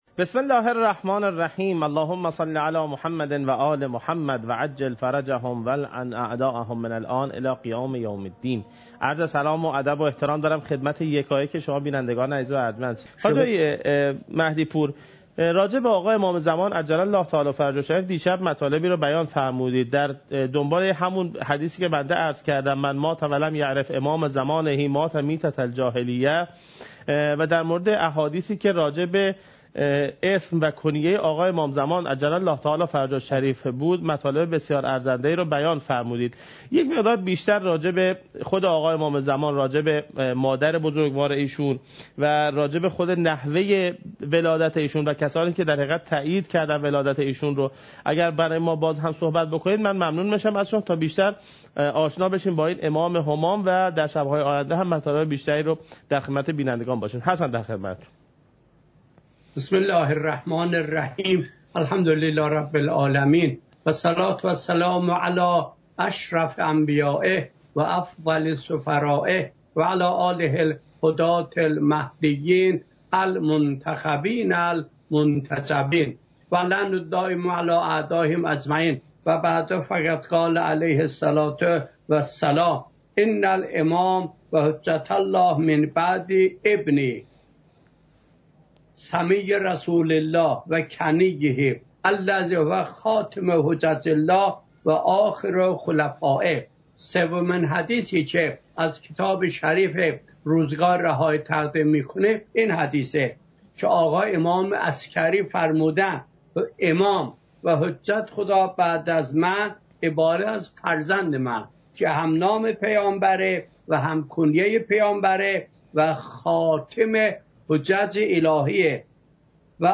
حجم: 8.38 MB | زمان: 35:19 | تاریخ: 1441هـ.ق | مکان: کربلا